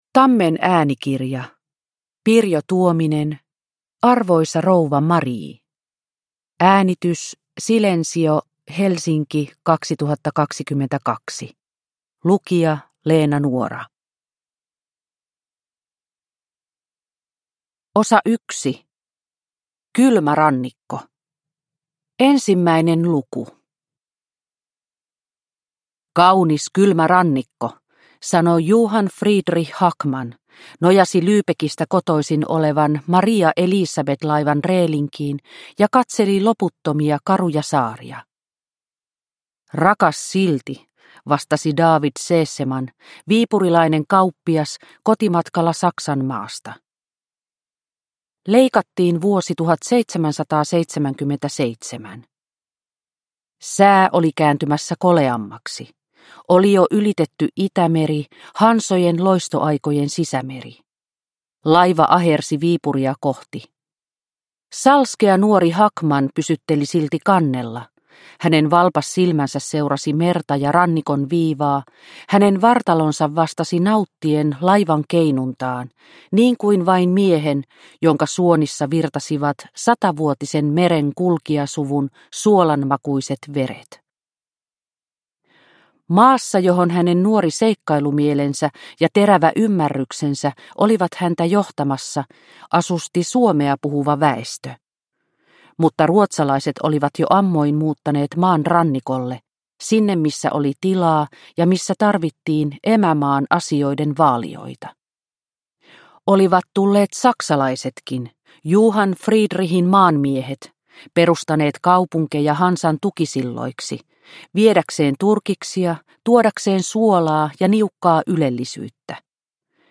Arvoisa rouva Marie – Ljudbok – Laddas ner